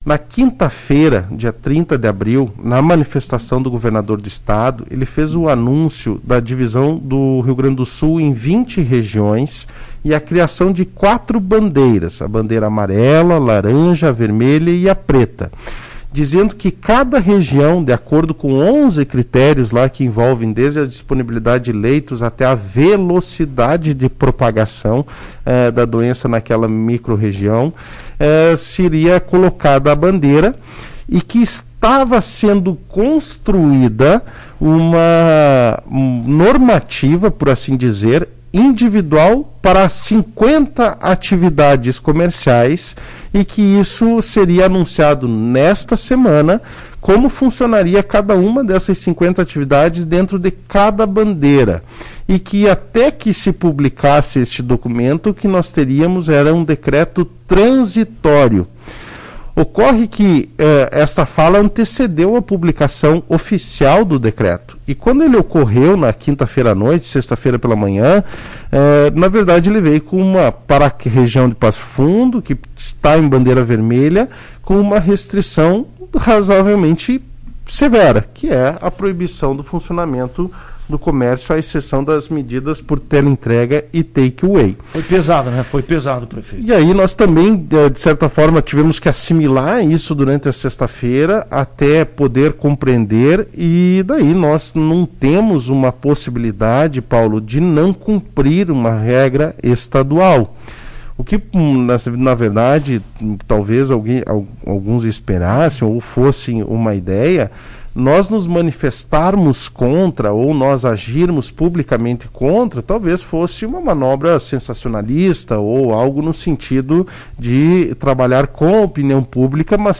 Prefeito Gustavo Bonotto falou sobre o resultado dessa reunião.